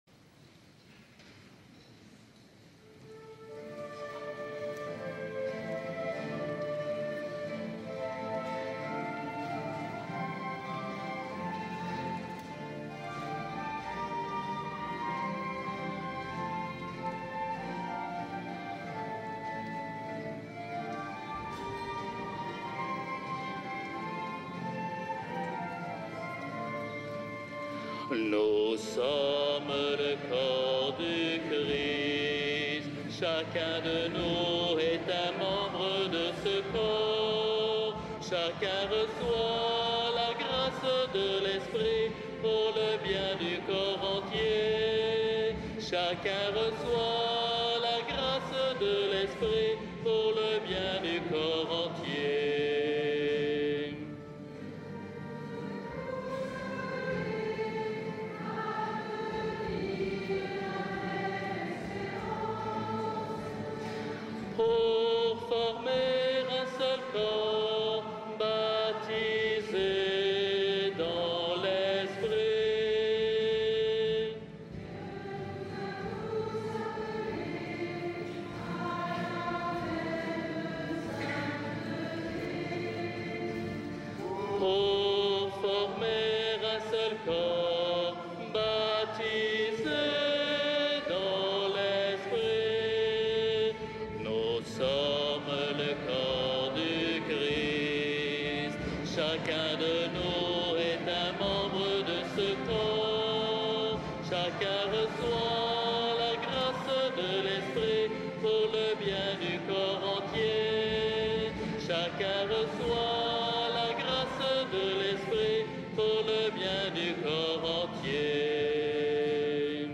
A l'intérieurs des chants font vibrer les voutes séculaires et arrivent à se faire entendre de l'extérieur.
Bibliothèque sonore – messes, AD ect
Messe filmée dédicace de l'autel